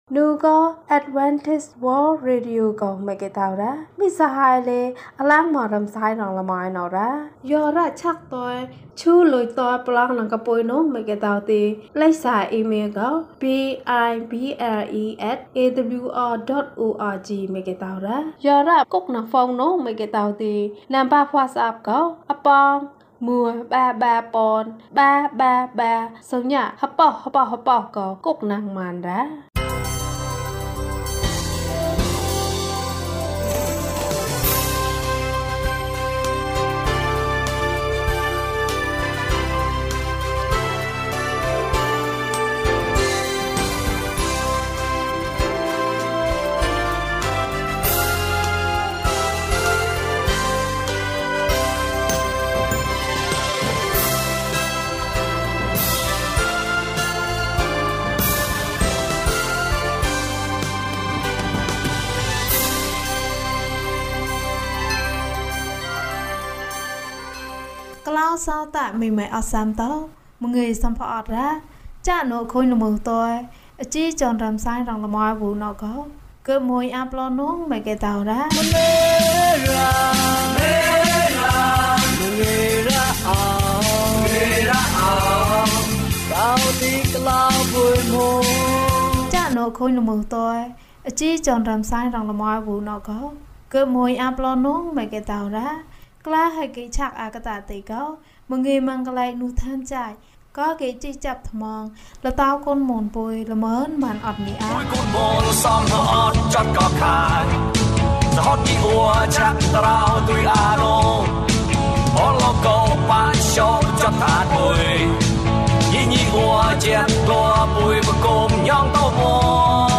အိုဘုရားသခင်၊ ကိုယ်တော်၏လက်ဖြင့် အကျွန်ုပ်ကို တို့တော်မူပါ။ ကျန်းမာခြင်းအကြောင်းအရာ။ ဓမ္မသီချင်း။ တရားဒေသနာ။